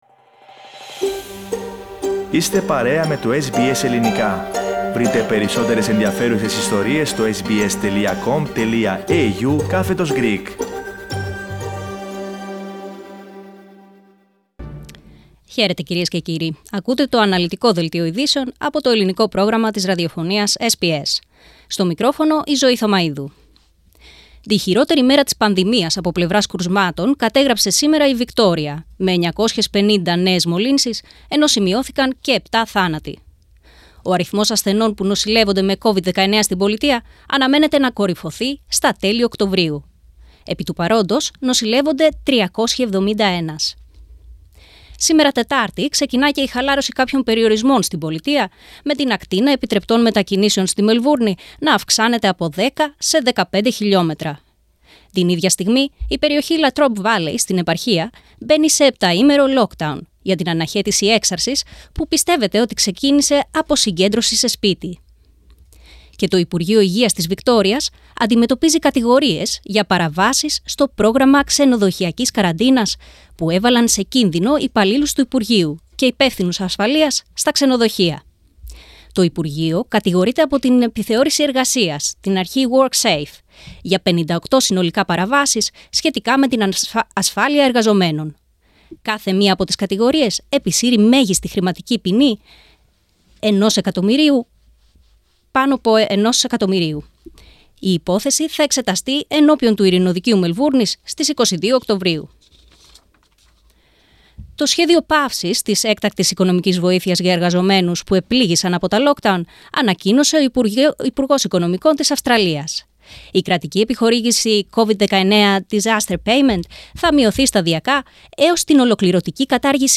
Δελτίο Ειδήσεων 29.09.21